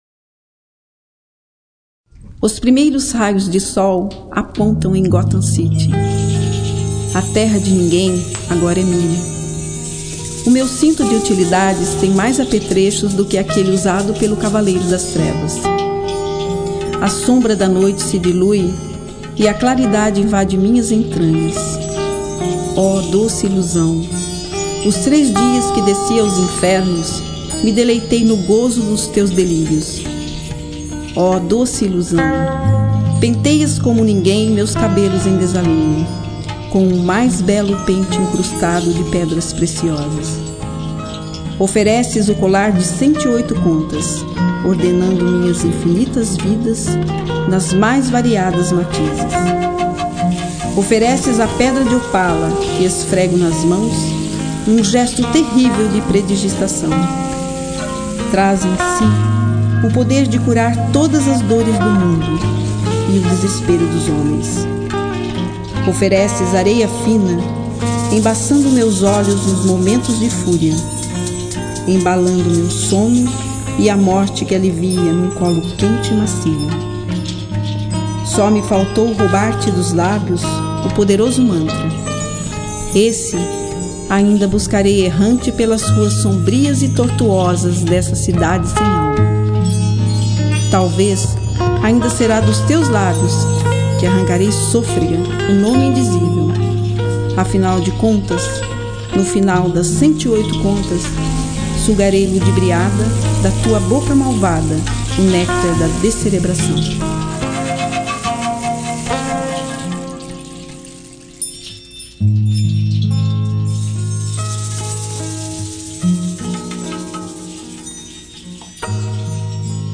Disponibilizo abaixo, meus três poemas gravados nos estúdios da Fundação Cultural Cassiano Ricardo na produção do CD Cidade das Palavras (2006) em São José dos Campos